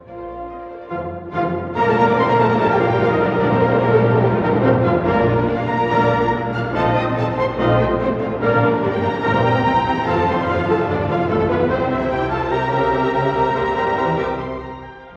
↑古い録音のため聴きづらいかもしれません！（以下同様）
Allegro ma non troppo
～快速に、ただし甚だしくなく～
上昇音型がメインの、とても活気に満ちた最終楽章です。
まるで喜びの感情が抑えきれないかのよう。